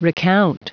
Prononciation du mot recount en anglais (fichier audio)
Prononciation du mot : recount